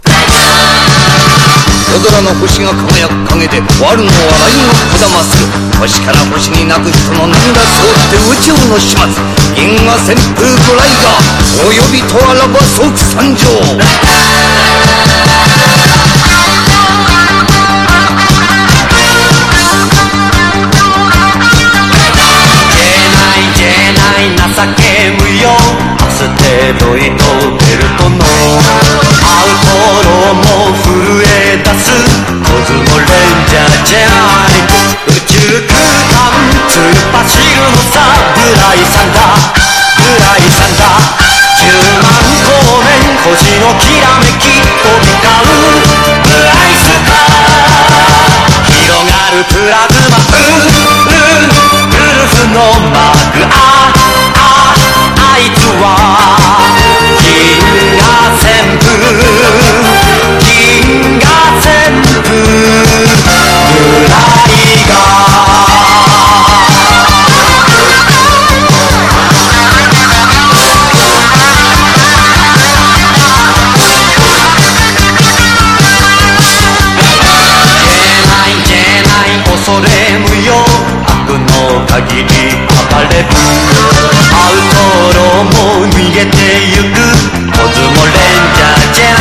ポピュラー# SOUNDTRACK / MONDO